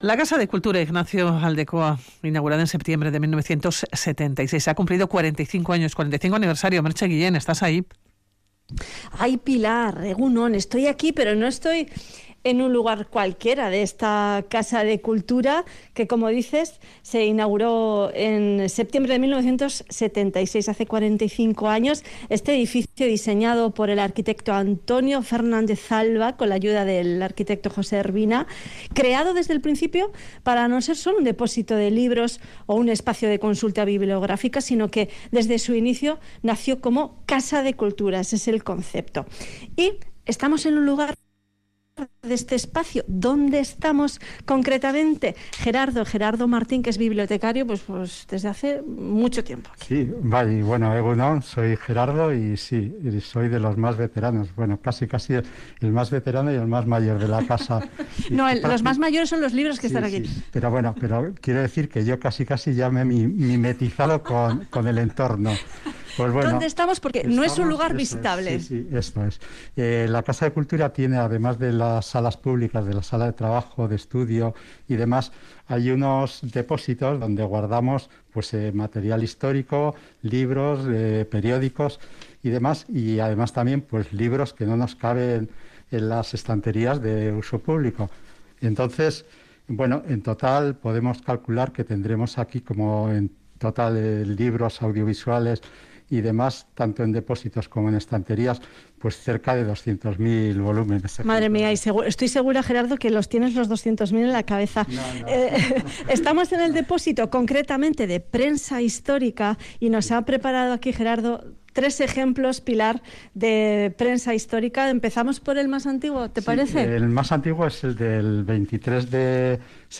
Publicado: 24/09/2021 09:13 (UTC+2) Última actualización: 24/09/2021 09:13 (UTC+2) Nos colamos en uno de los espacios "no visitables" de la Casa de Cultura Ignacio Aldecoa, que ha cumplido 45 años de actividad.